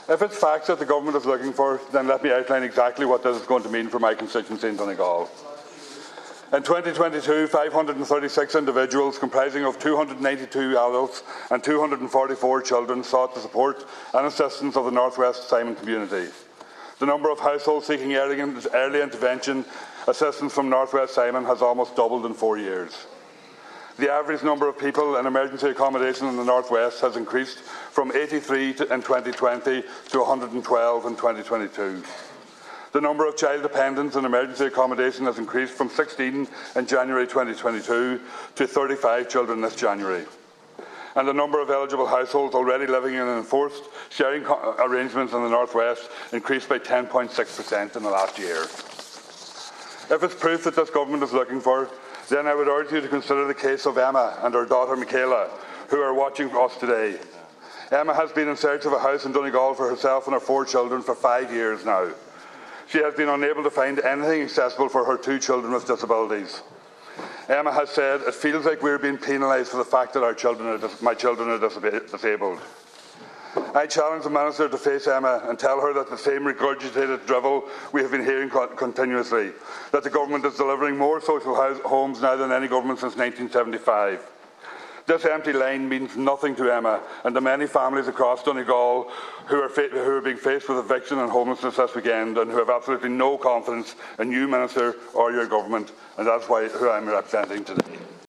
Donegal Deputy Thomas Pringle was speaking during Labour’s confidence motion in the Dail today during which he expressed his lack of confidence.